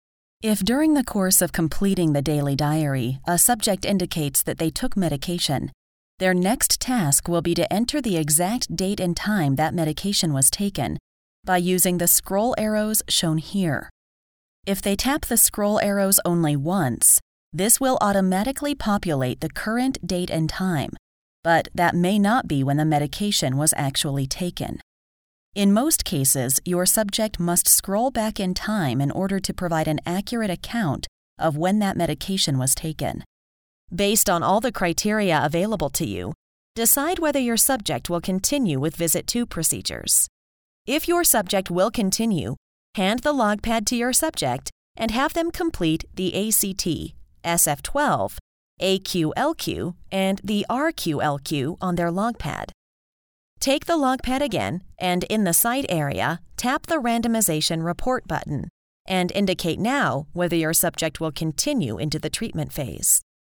American English Voice Over artist
Sprechprobe: Industrie (Muttersprache):